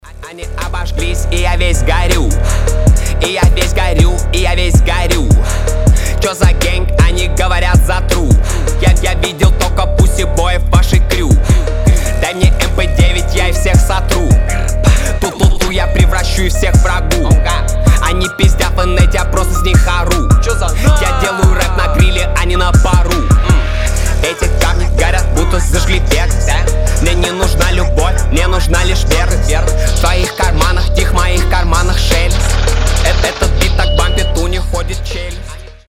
рэп , trap
мощные басы